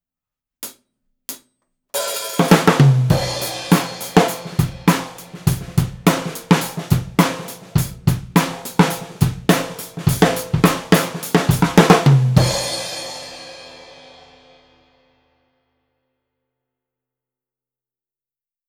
【サンプル音源あり】MXL V67G 一本のみでドラムレコーディング！
お次は、TOPにマイキングしました。
位置は、タムの真上からのイメージです。
低音感もしっかりあり、バランスのいいサウンドですね！